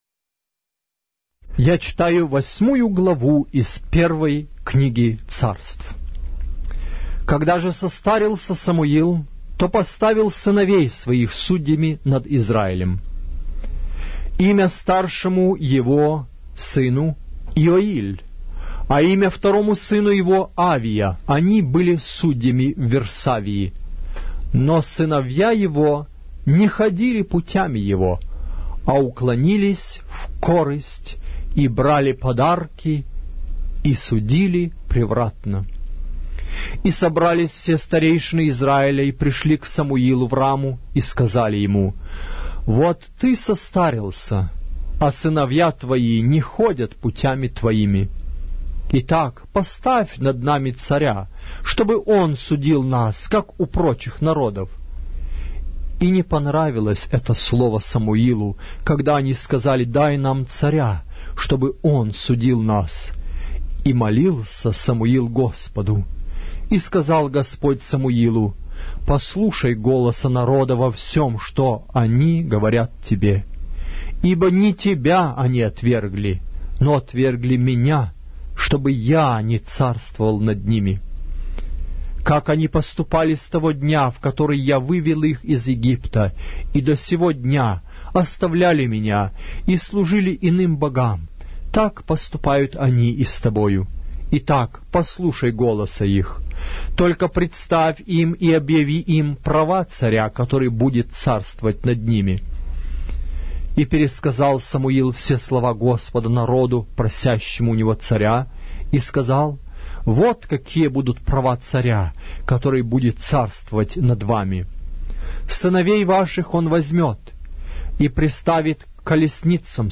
Глава русской Библии с аудио повествования - 1 Samuel, chapter 8 of the Holy Bible in Russian language